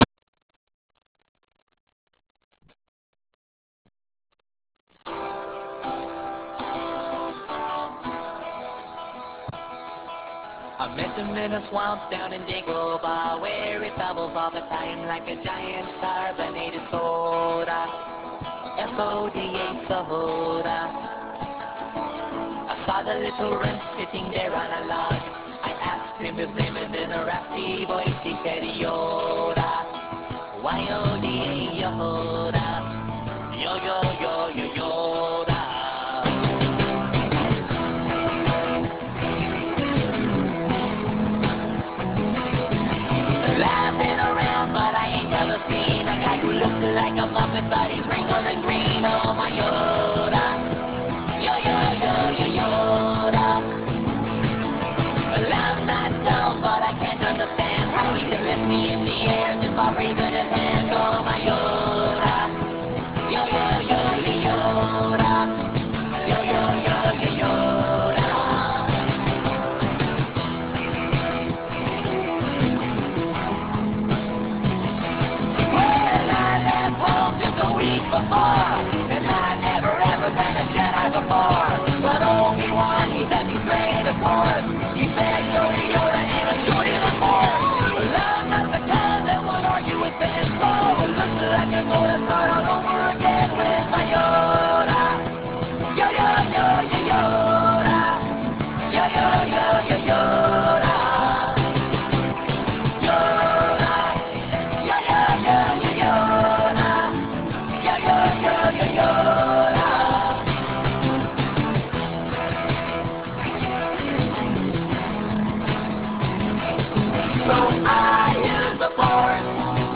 a parody